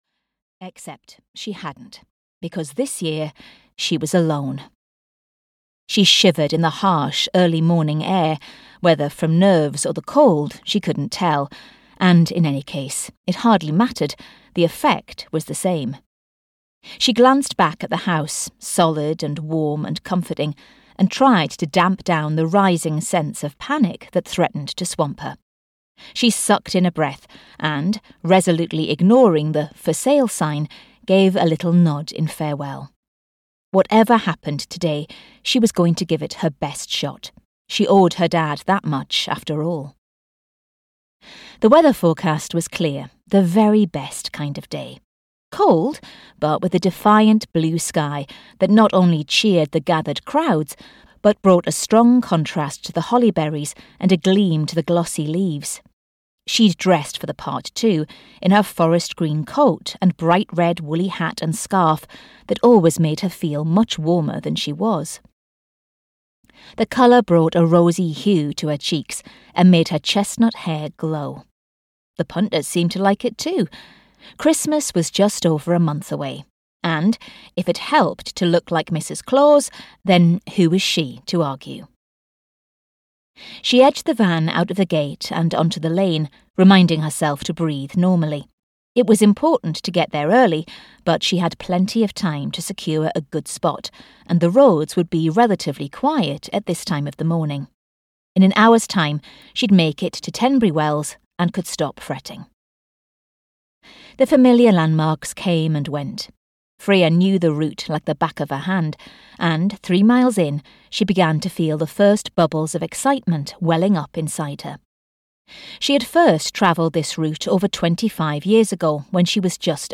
A Year at Appleyard Farm (EN) audiokniha
Ukázka z knihy